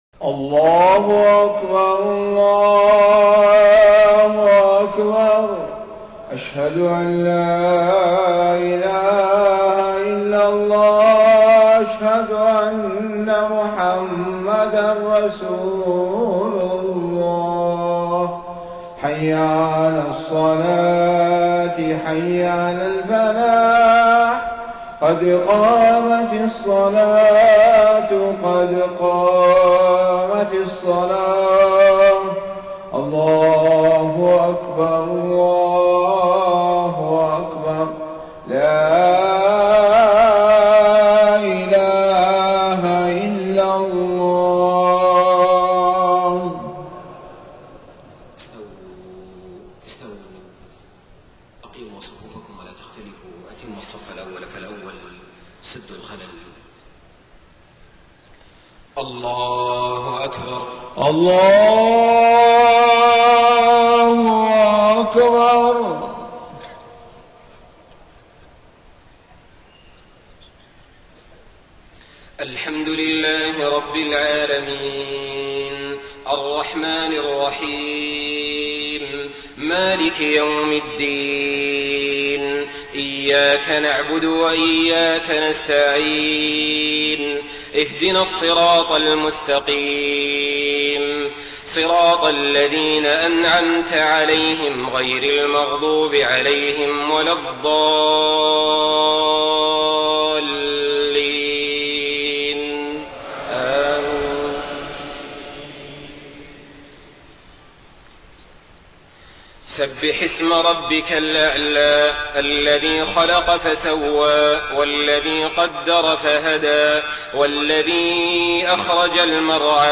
صلاة الجمعة 29 محرم 1431هـ سورتي الأعلى و الغاشية > 1431 🕋 > الفروض - تلاوات الحرمين